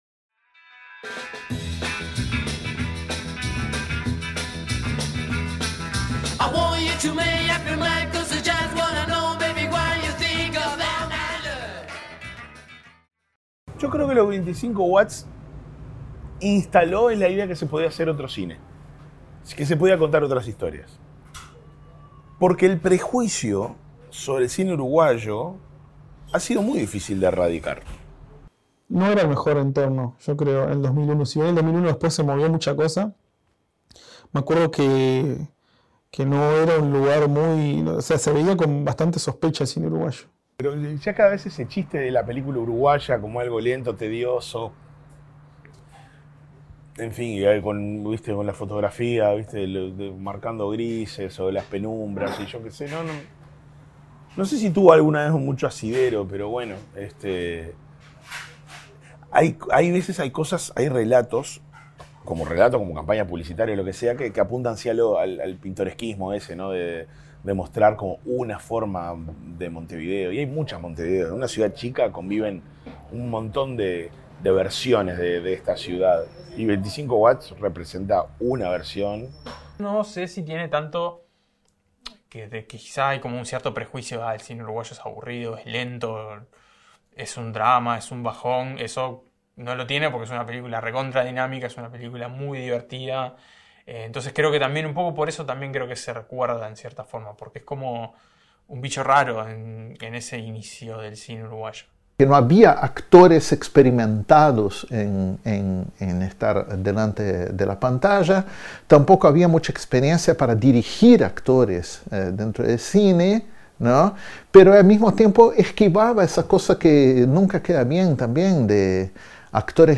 Conversamos con seis críticos del medio local, para conocer sus opiniones y reacciones a la película, los aspectos positivos y negativos, por qué es una película importante para el cine uruguayo y mucho más.
La música inicial pertenece al soundtrack de la película: Make Up Your Mind, interpretada por Los Mockers.